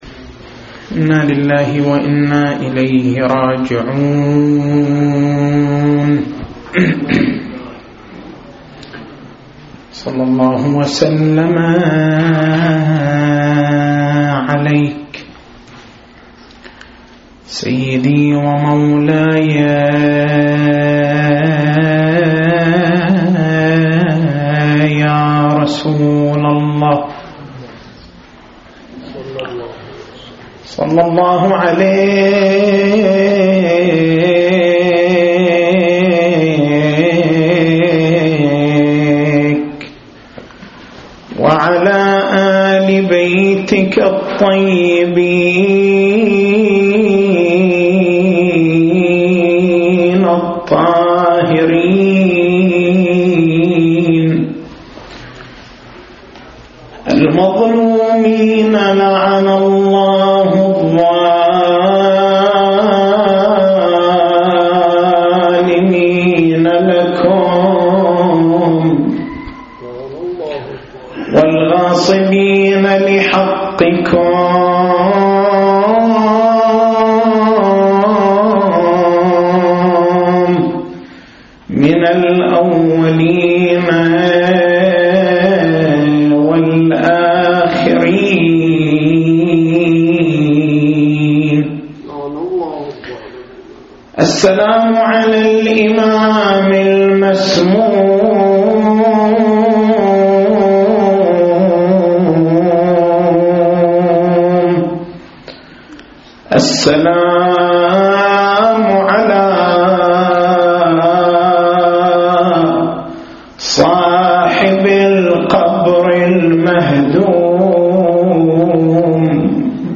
تاريخ المحاضرة: 08/03/1430 نقاط البحث: قوله (ع): إنَّ الغضب مفتاح كلّ شر قوله (ع): إذا كان المقضي كائنًا فالضراعة لماذا؟ قوله (ع): لا يشغلك رزق مضمون عن عمل مفروض.